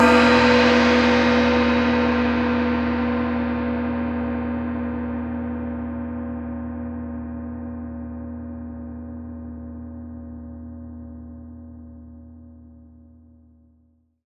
Index of /90_sSampleCDs/Partition E/MIXED GONGS